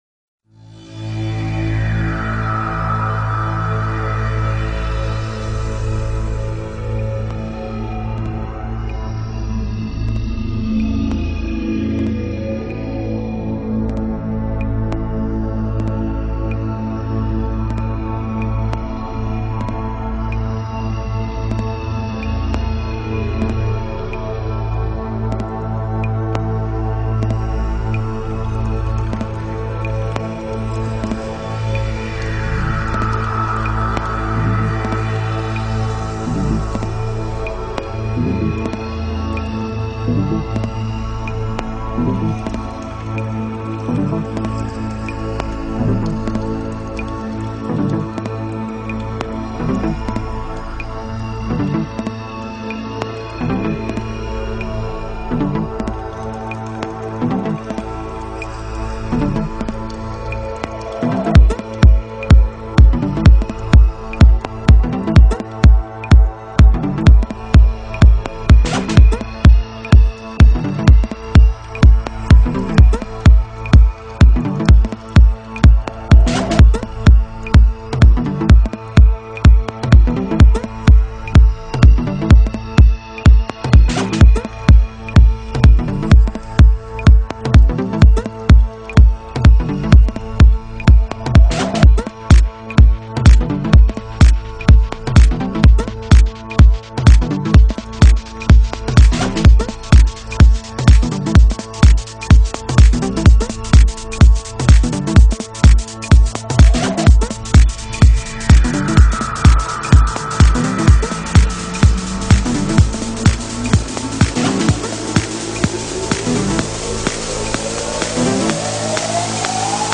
trance progresivo